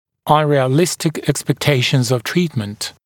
[ˌʌnrɪə’lɪstɪk ˌekspek’teɪʃnz əv ‘triːtmənt][ˌанриэ’листик ˌэкспэк’тэйшнз ов ‘три:тмэнт]нереалистичные ожидания от лечения, ожидание от лечения нереалистичных результатов